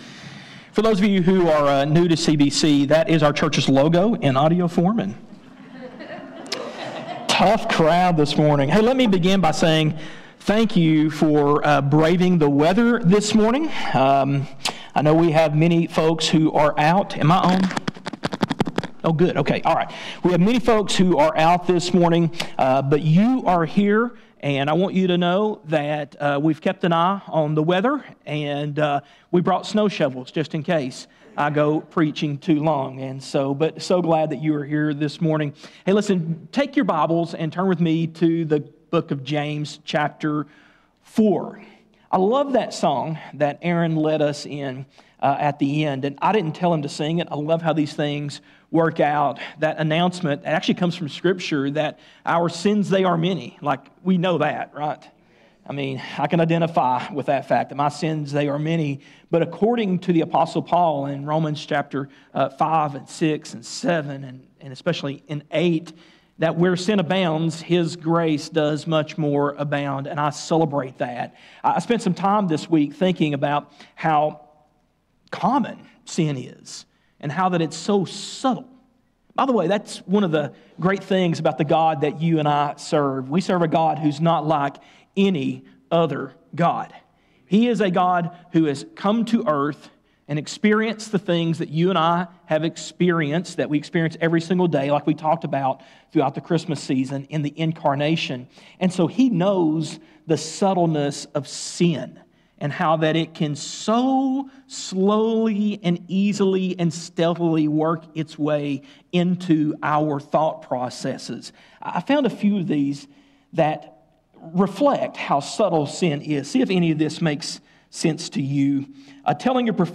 A message from the series "First, Things First."